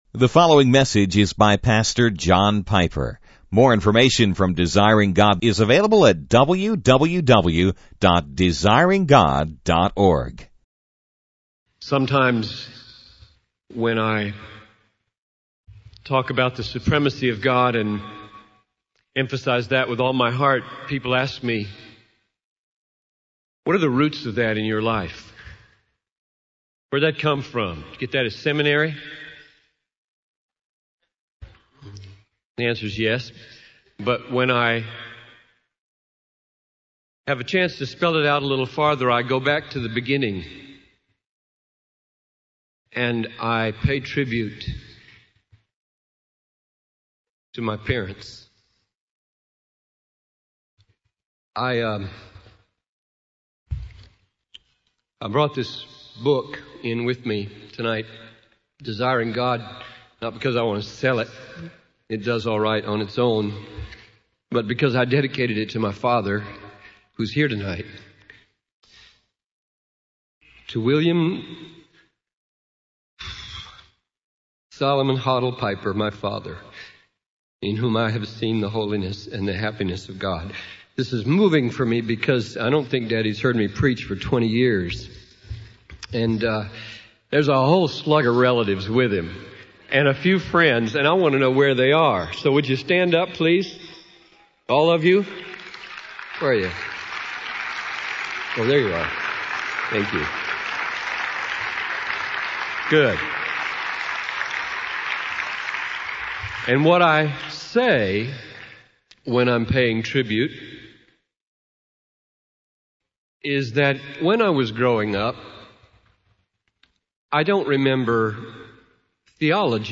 In this sermon, the speaker discusses the supremacy of God in missions. He outlines three main points: the promise is sure, the price is suffering, and the prize is satisfying.